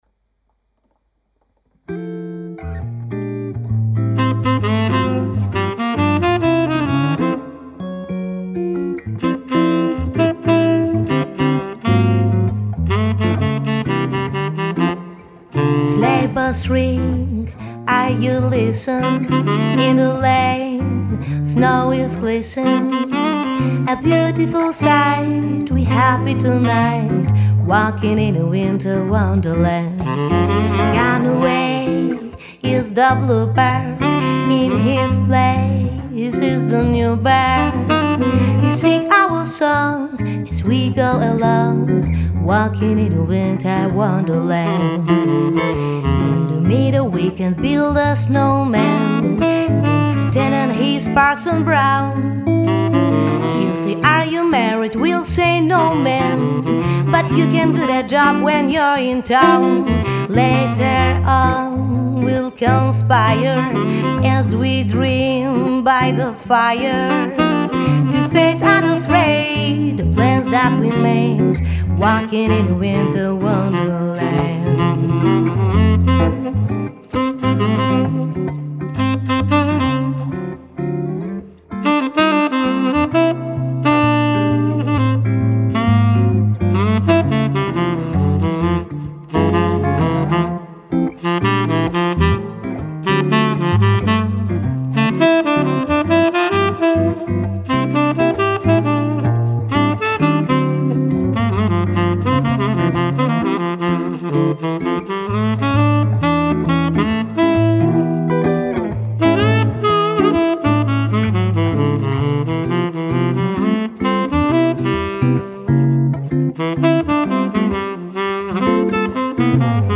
saksofon - wokal - gitara jazzowa